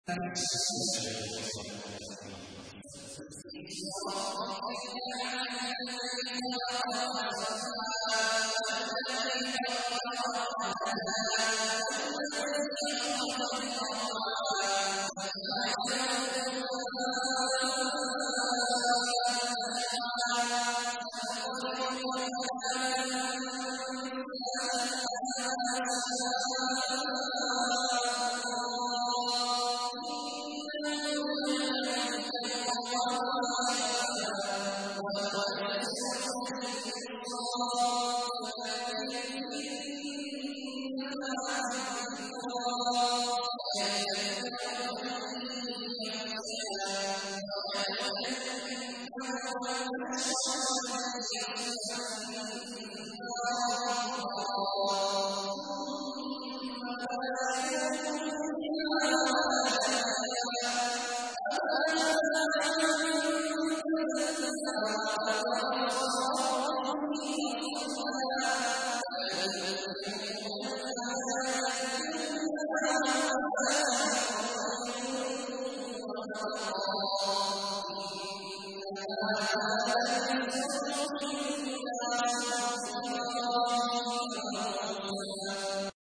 تحميل : 87. سورة الأعلى / القارئ عبد الله عواد الجهني / القرآن الكريم / موقع يا حسين